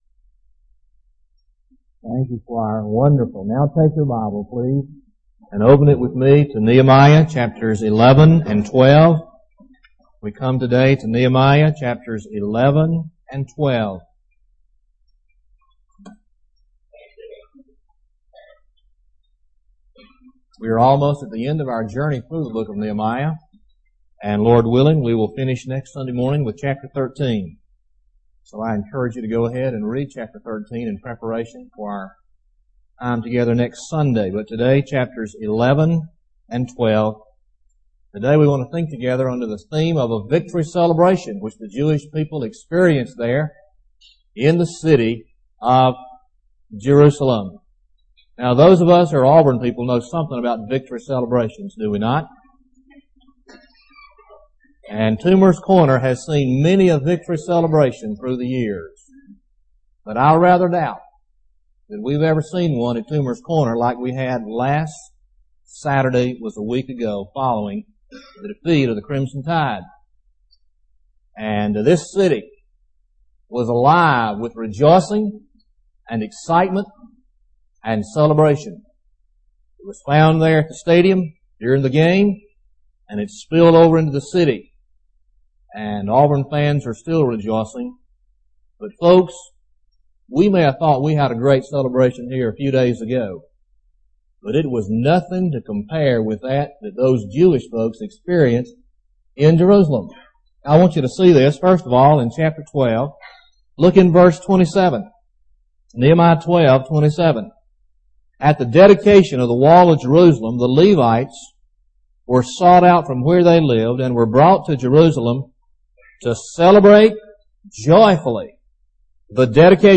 Victory Celebration Sermon